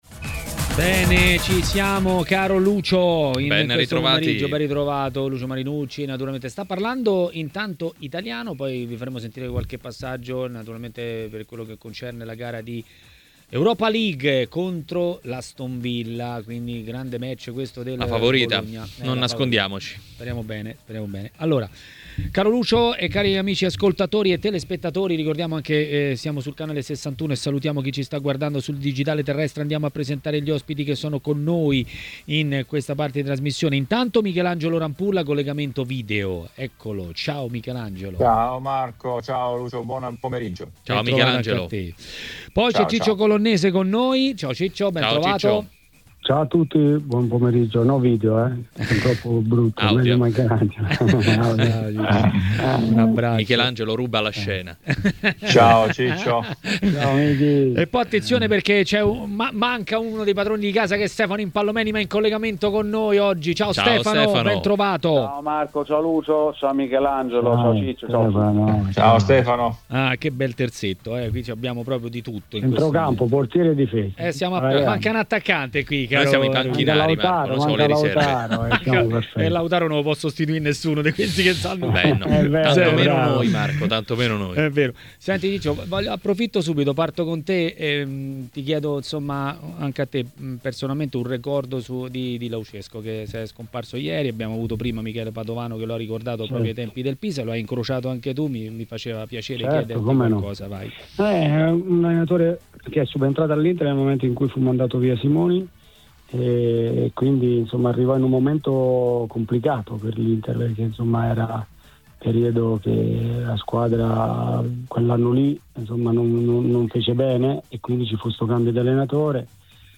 A Maracanà, nel pomeriggio di TMW Radio, è arrivato il momento dell'ex portiere Michelangelo Rampulla.